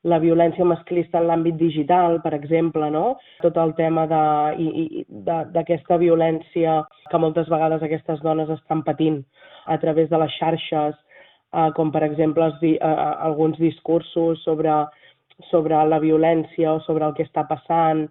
Mariceli Santarén en declaracions a Ràdio Calella TV.